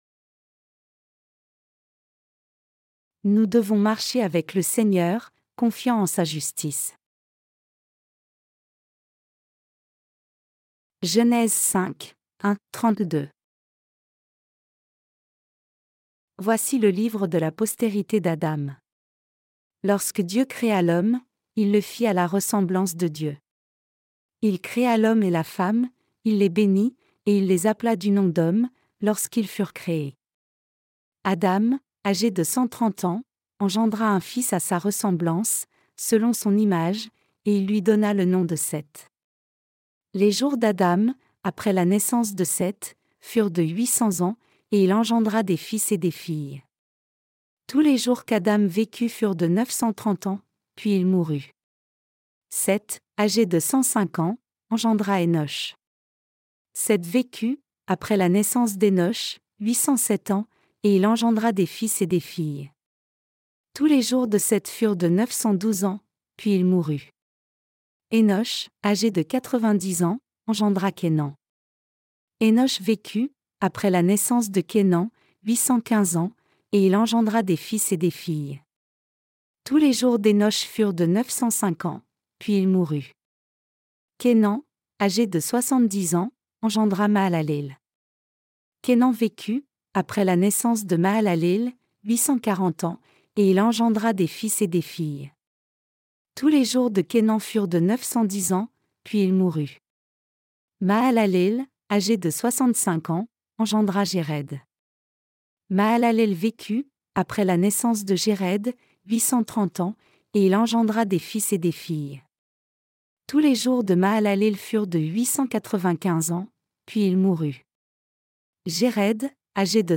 Sermons sur la Genèse (V) - LA DIFFERENCE ENTRE LA FOI D’ABEL ET LA FOI DE CAÏN 10.